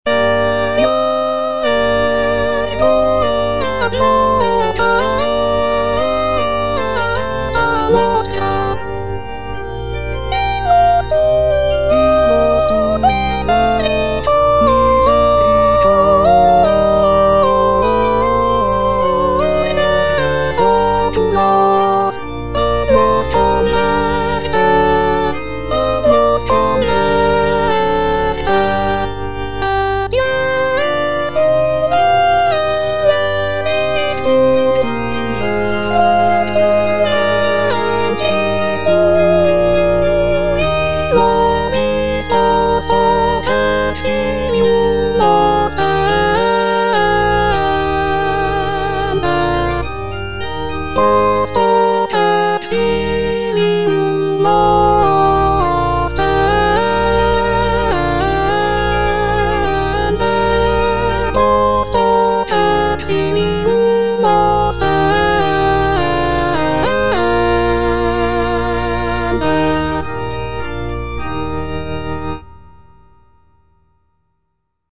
Choralplayer playing Salve Regina in C minor by G.B. Pergolesi based on the edition IMSLP #127588 (Breitkopf & Härtel, 15657)
G.B. PERGOLESI - SALVE REGINA IN C MINOR Eja ergo advocata nostra - Soprano (Emphasised voice and other voices) Ads stop: auto-stop
"Salve Regina in C minor" is a sacred choral work composed by Giovanni Battista Pergolesi in the early 18th century.